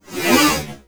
ROBOTIC_Movement_04_mono.wav